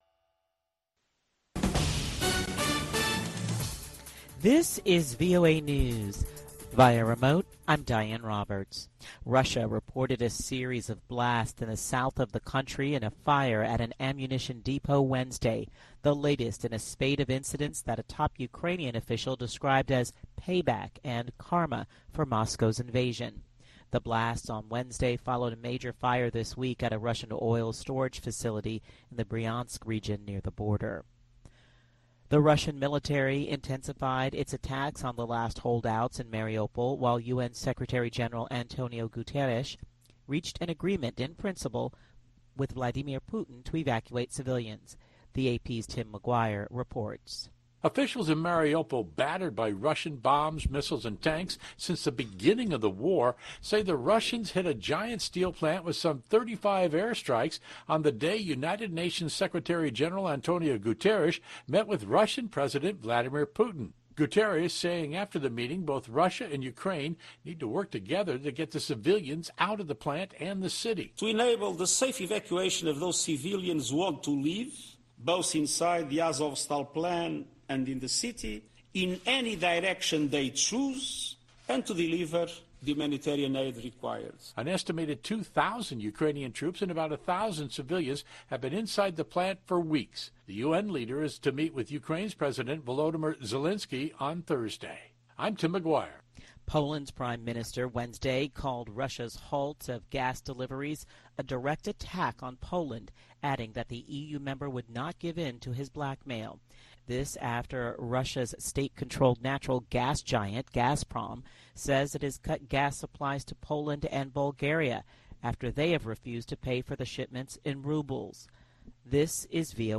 Voice of America: VOA Newscasts